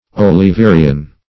Search Result for " oliverian" : The Collaborative International Dictionary of English v.0.48: Oliverian \Ol`i*ve"ri*an\, n. (Eng.